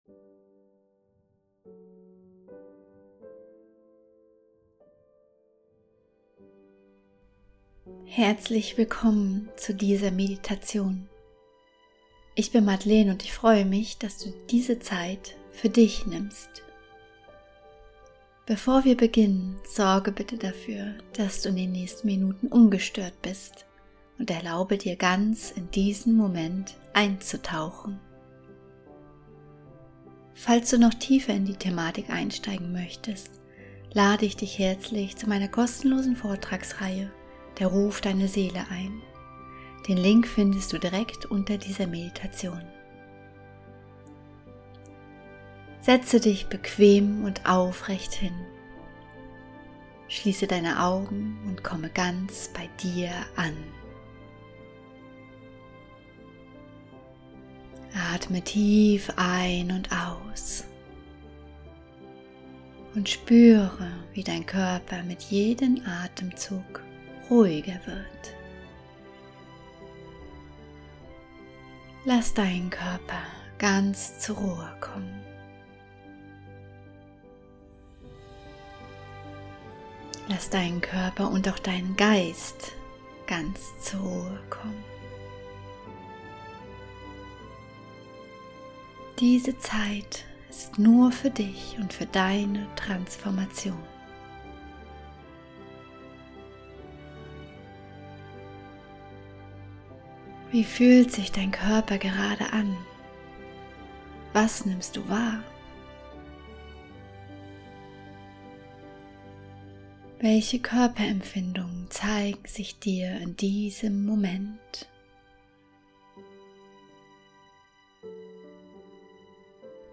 18-Min Meditation: Wenn Blockaden immer wiederkommen ~ Heimwärts - Meditationen vom Funktionieren zum Leben Podcast